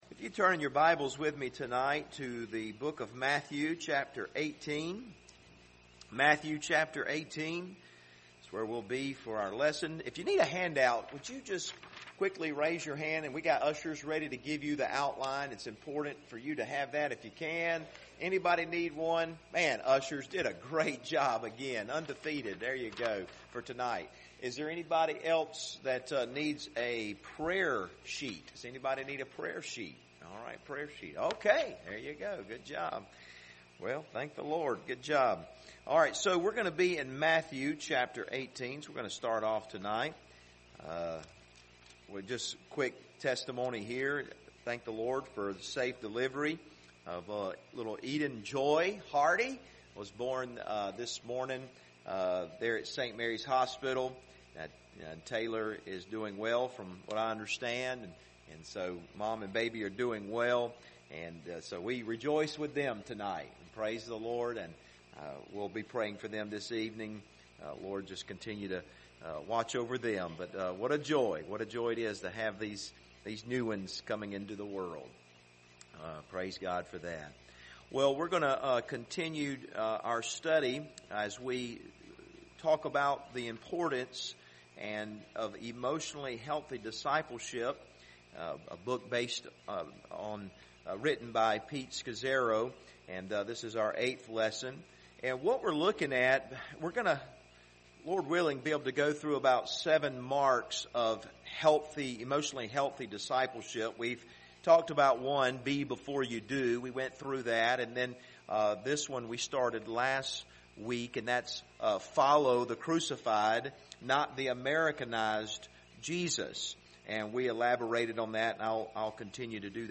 Passage: Matthew 18 Service Type: Wednesday Evening